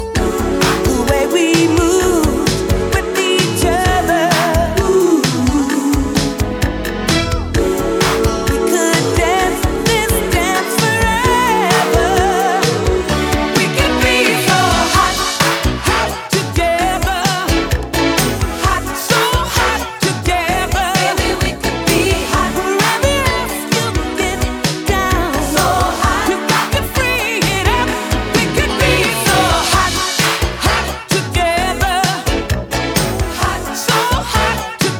Жанр: Поп музыка / R&B / Соул